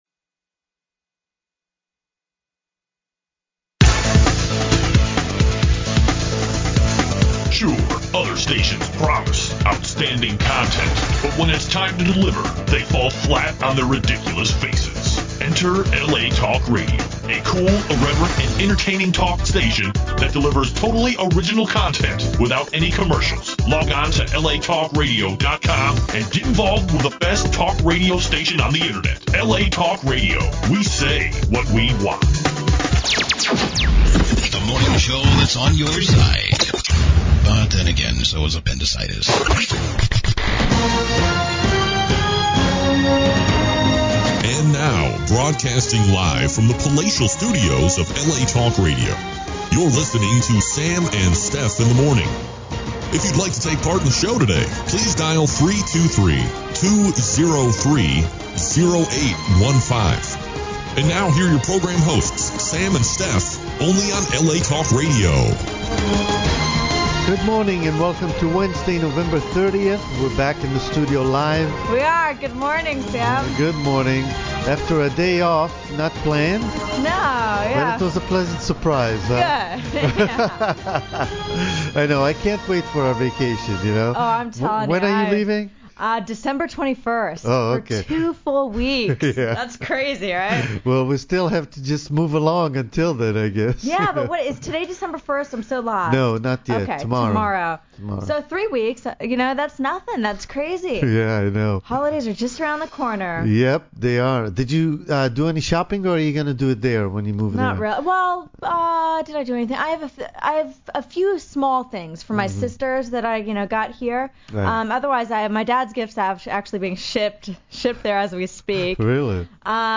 The show features a breadth of amazing guests and callers to keep you entertained.
Enjoy your morning at the office with spicy talk and even some cool music. Call in live and chat with us on the air.